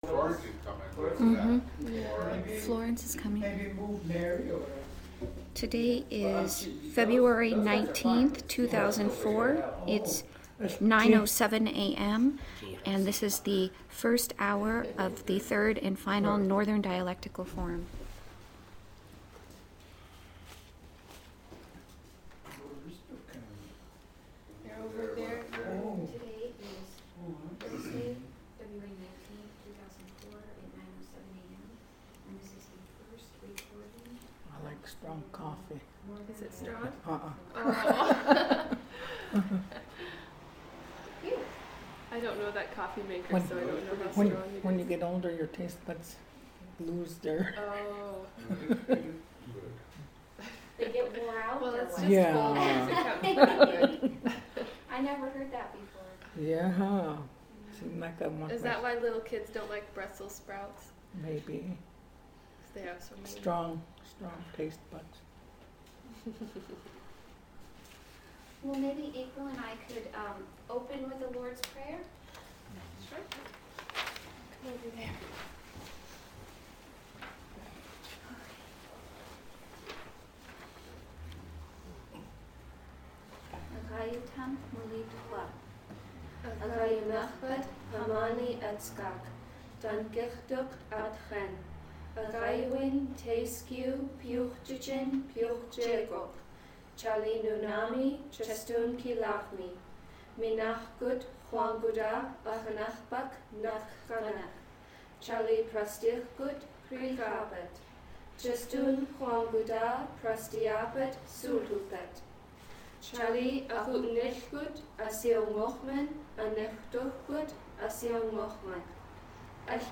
First Taping of Northern Dialect Forum 1B Part 1 | Alutiiq Museum Collections
Access audio Description: Original Format: MiniDisc (AM470:123A) Migration: CD (AM470:123B) Recording length (file size, time): 761.8 MB; 1:11:59 Location: Location Description: Kodiak, Alaska